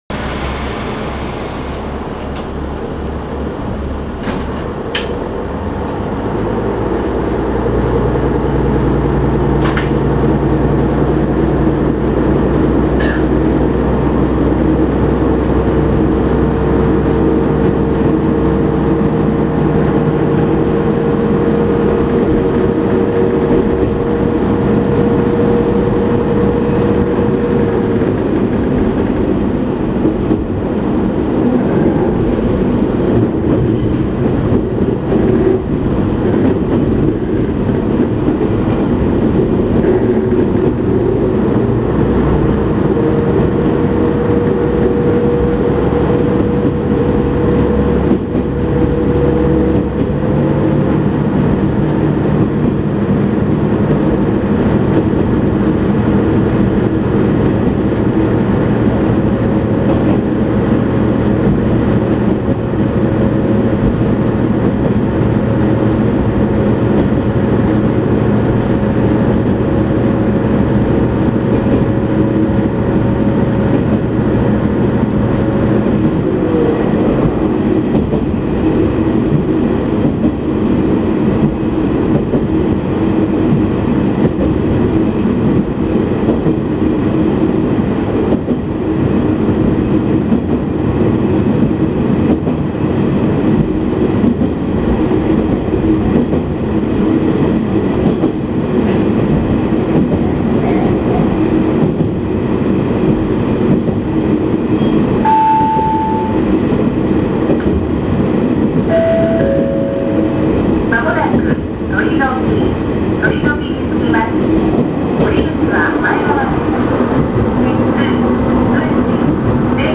・キハ32 走行音
【予讃線】伊予市〜鳥ノ木（2分31秒：824KB）
動き出してしまえばごく普通のディーゼルカーの音です。車体も短く、重量も軽いので軽やかに走るイメージでした。